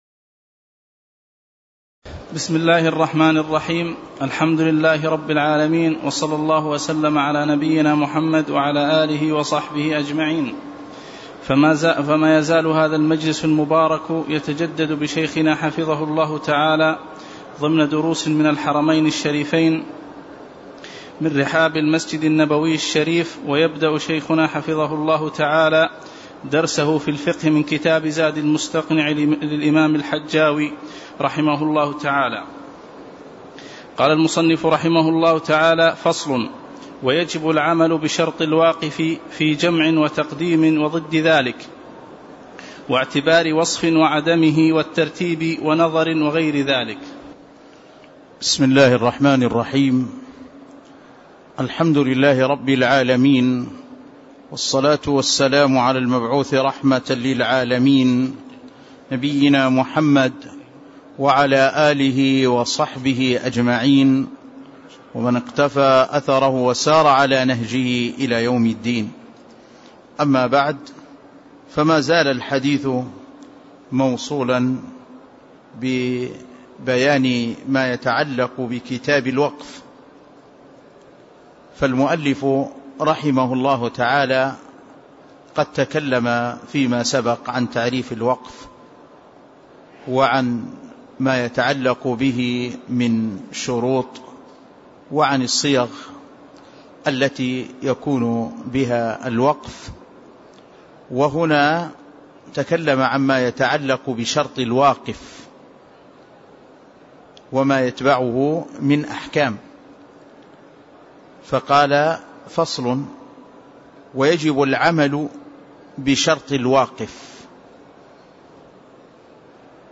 تاريخ النشر ٤ ربيع الأول ١٤٣٧ هـ المكان: المسجد النبوي الشيخ